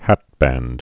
(hătbănd)